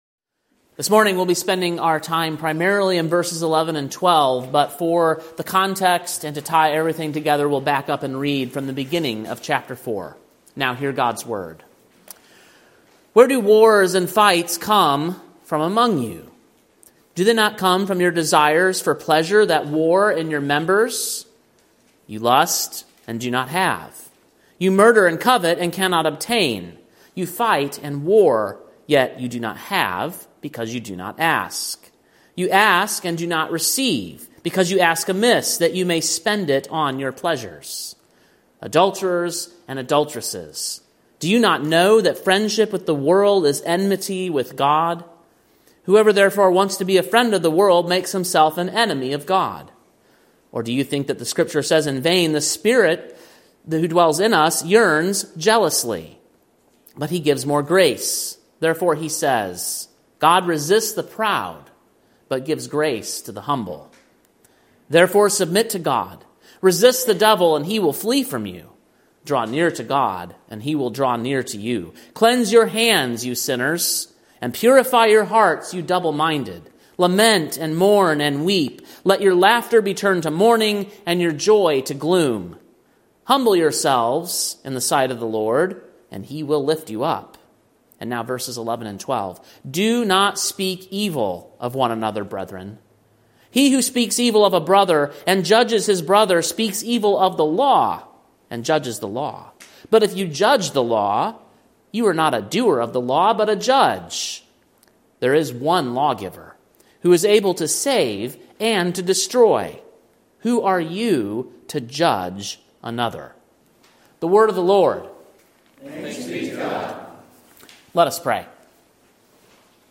Sermon preached on February 23, 2025, at King’s Cross Reformed, Columbia, TN. A church plant of the CREC.